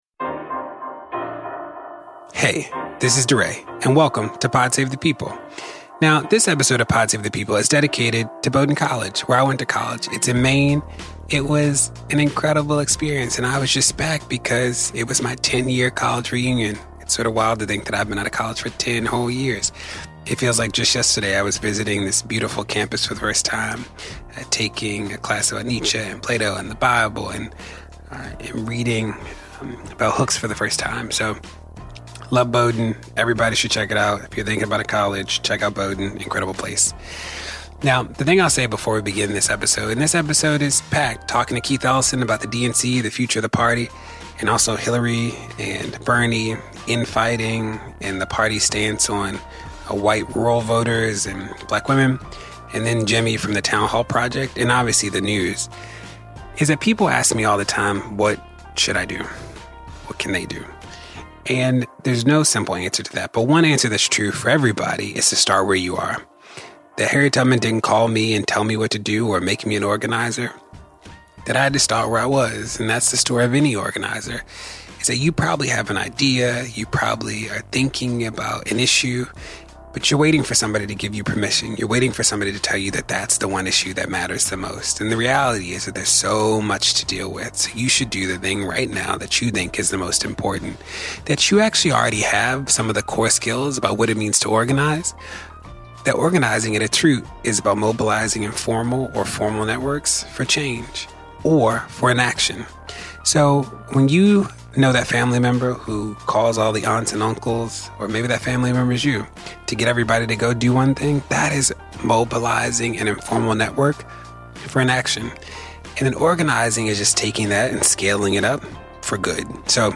DeRay talks candidly with Congressman and DNC Deputy Chair Keith Ellison (MN-5th) about Resistance Summer, who’s on the 2020 Presidential bench, Comey’s upcoming testimony, Kushner’s security clearance, engaging black women in the DNC, and why we have to keep fighting for just...